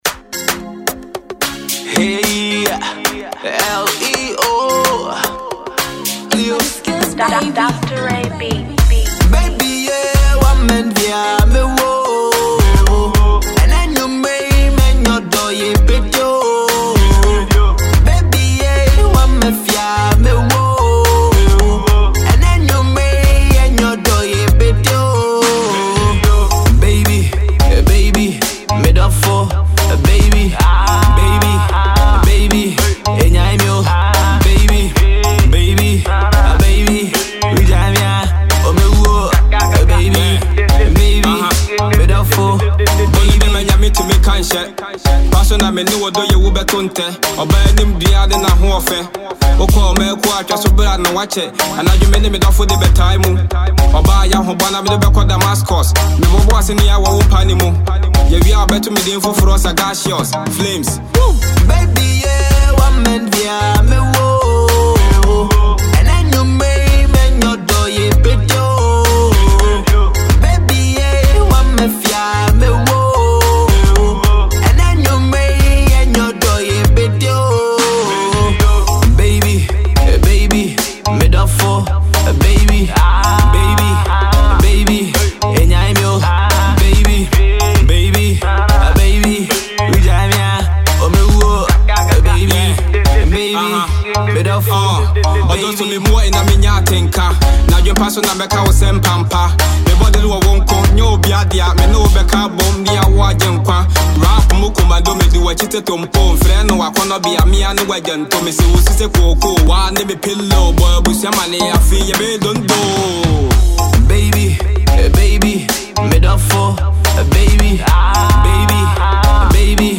GHANA MUSIC
love tune